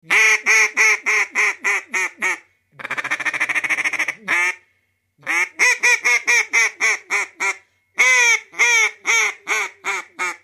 Duck - утка
Отличного качества, без посторонних шумов.